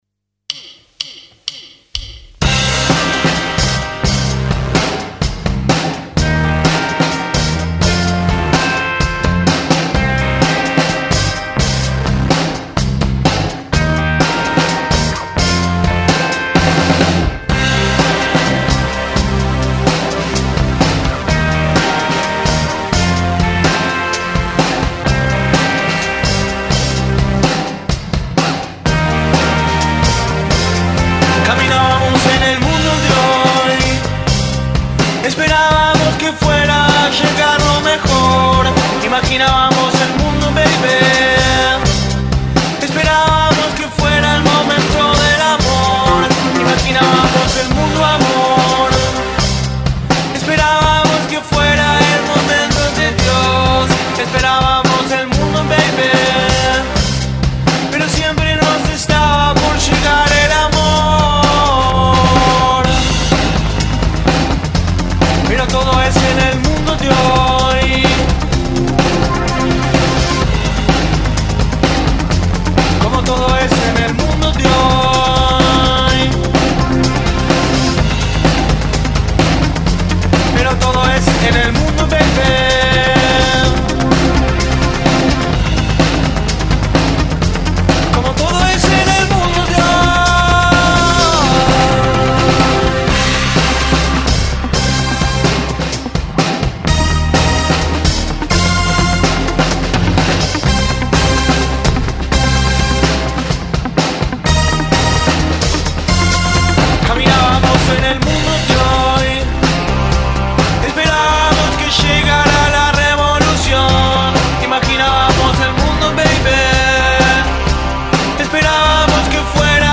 Baterías
guitarras
bajos y sintes
Syntes y voces
Grabado en vivo en estudios ION